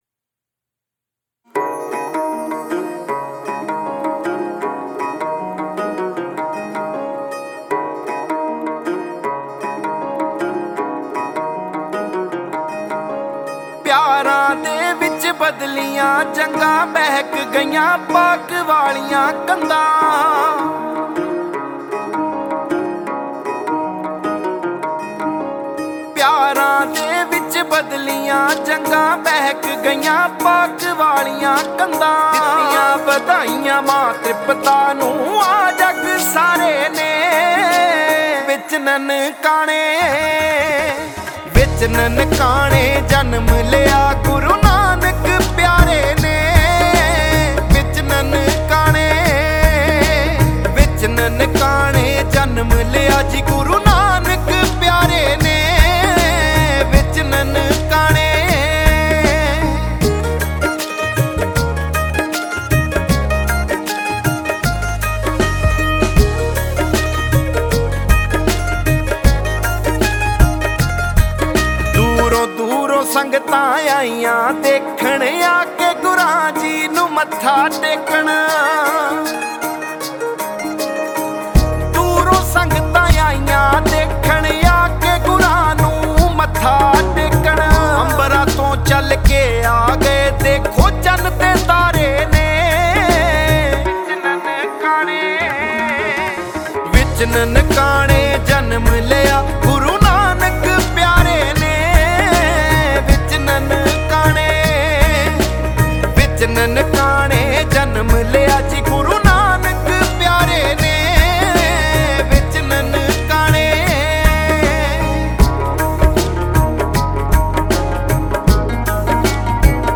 Category: Shabad Gurbani